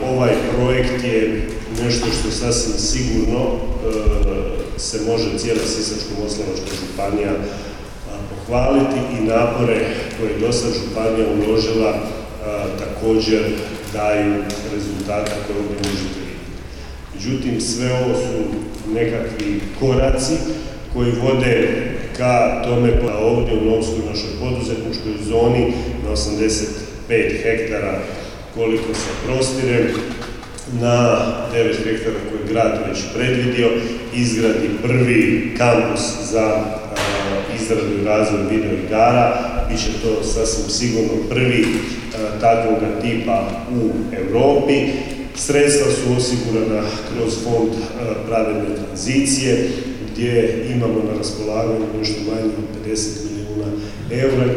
Novska ima prvi, za sada i jedini, poduzetnički inkubator u Hrvatskoj koji je specijaliziran za izradu videoigara, uz Sisak u Srednjoj školi Novska obrazuje se za zanimanje tehničar u izradi videoigara, otvoren je u Novskoj i prvi učenički dom, upravo zato jer se u Novskoj za ovo zanimanje obrazuju učenici iz gotovo cijele Hrvatske, rekao je u Novskoj ministar rada, mirovinskog sustava, obitelji i socijalne politike Marin Piletić